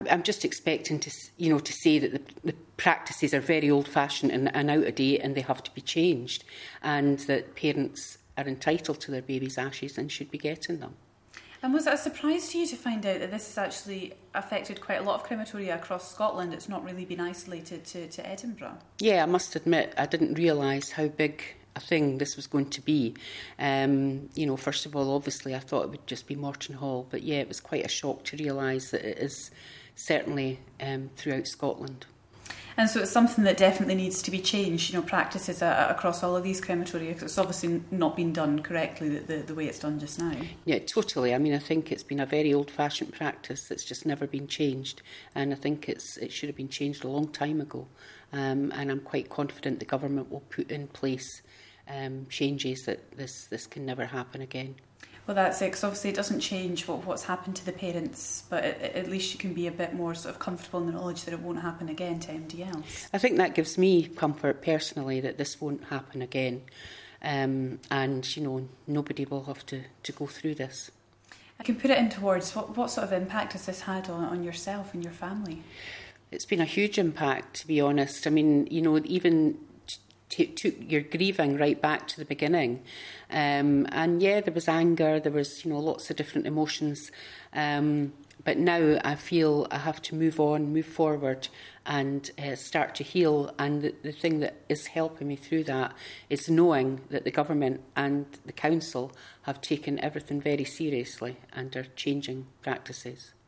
A major report into the disposal of baby ashes at Scottish crematoria will be published later, following the scandal at Mortonhall Crematorium in Edinburgh where remains were disposed of without the knowledge of grieving mums and dads. Forth News has been speaking to one of those parents